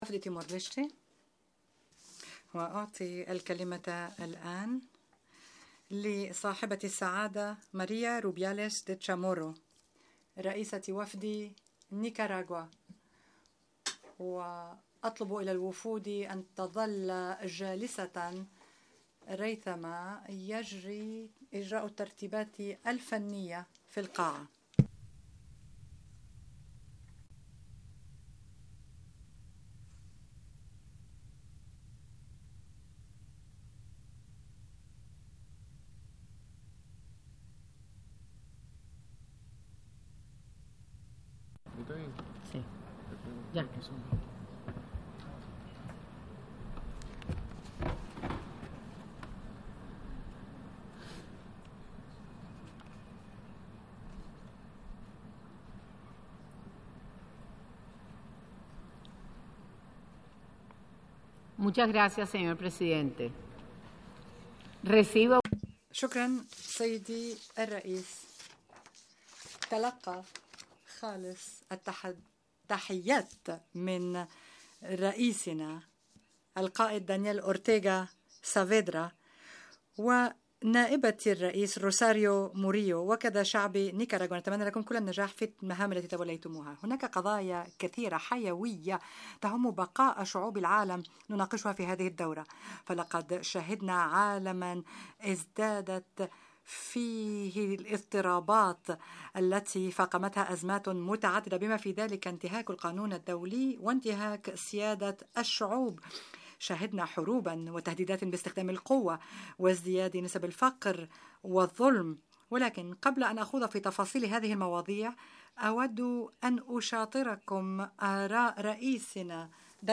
H.E. Mrs. María Rubiales de Chamorro spoke at the General Debate of the 72nd Session of the General Assembly